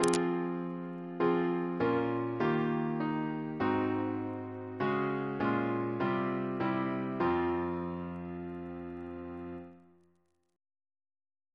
Single chant in F minor